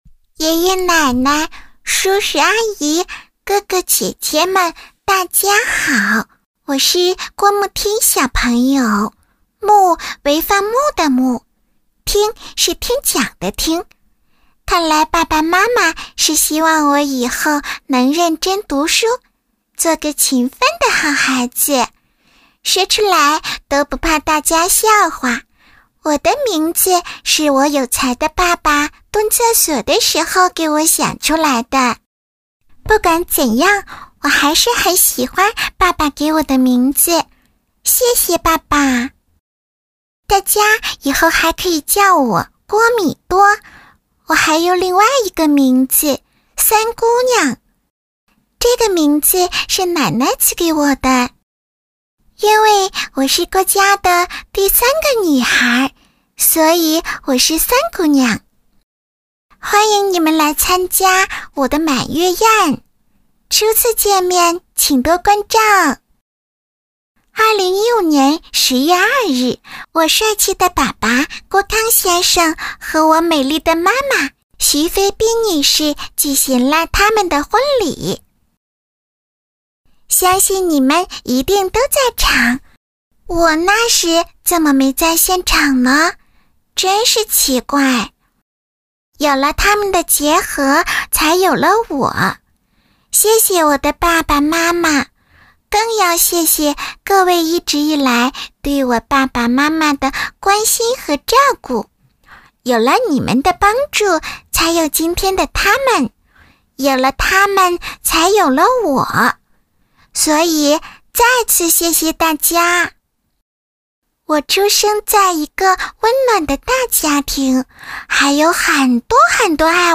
女93专题广告 v93